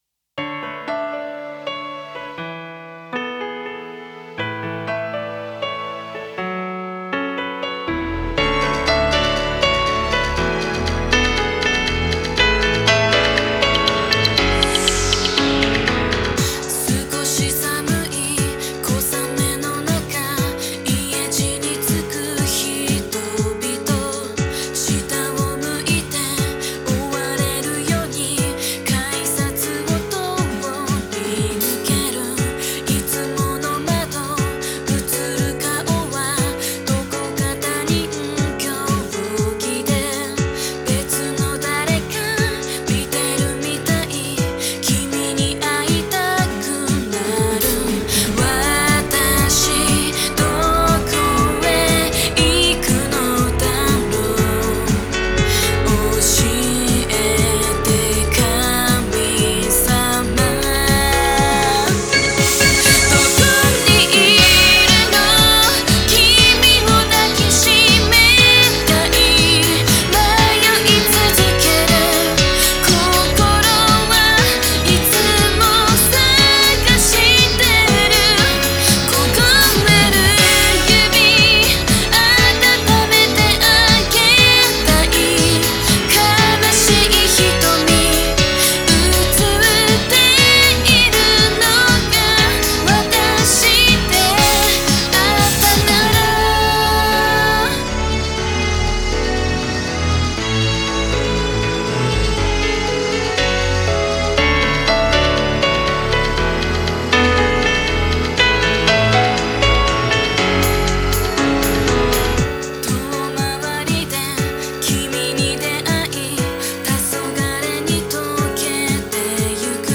「歌モノ部門」最優秀作品です。